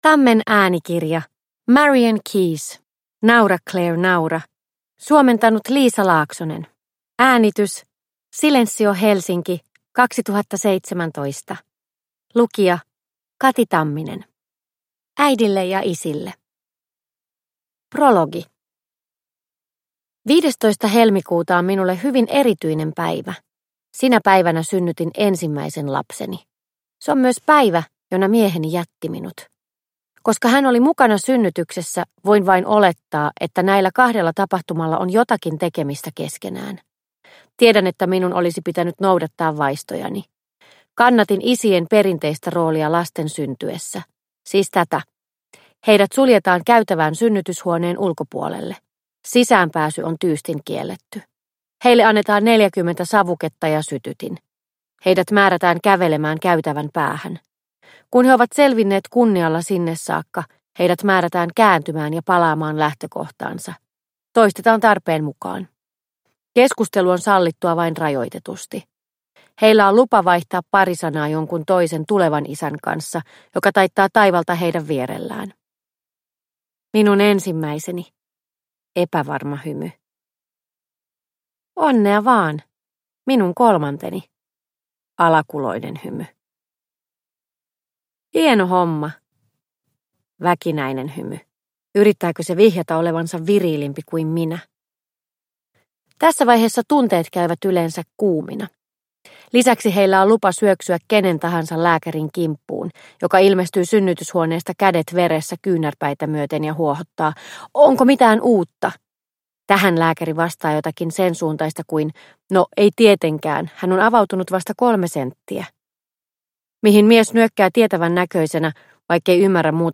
Naura, Claire, naura – Ljudbok – Laddas ner